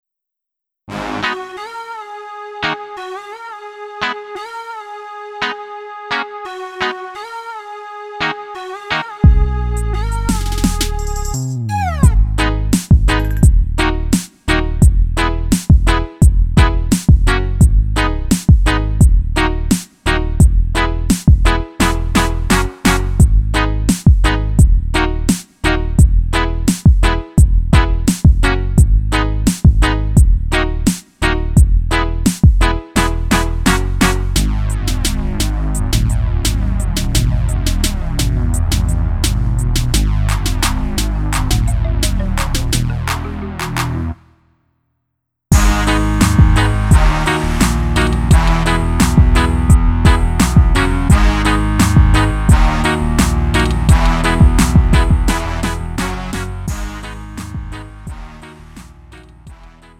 음정 원키 3:11
장르 가요 구분